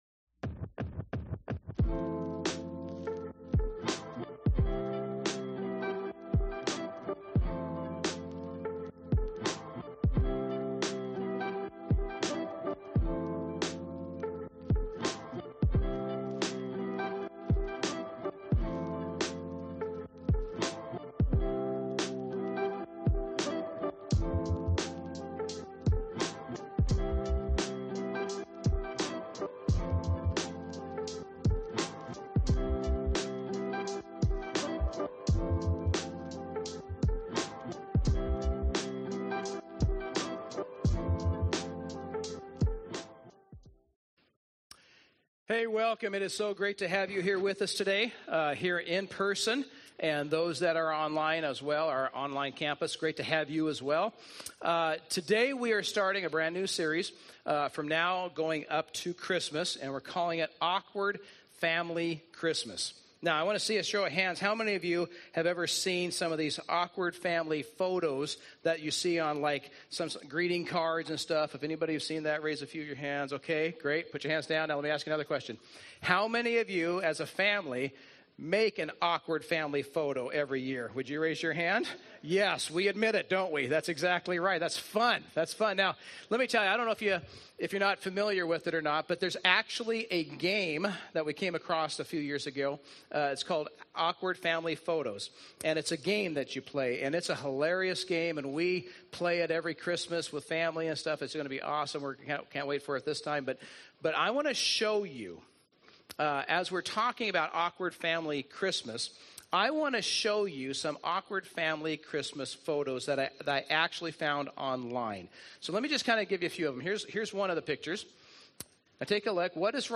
A message from the series "Do Not Try this at Home."